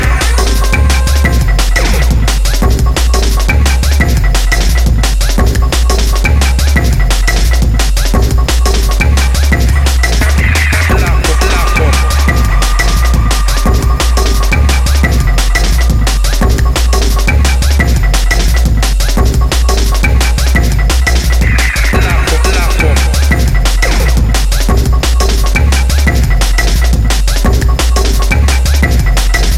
TOP > Jump Up / Drum Step